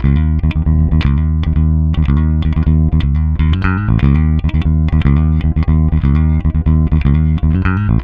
-JP THROB D#.wav